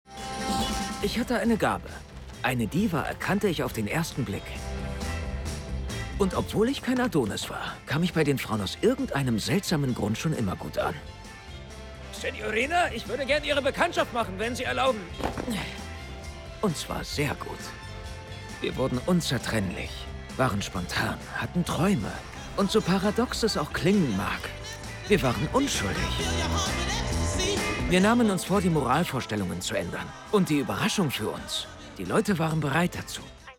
Klar, markant, frisch, wandelbar, witzig, sinnlich.
Filme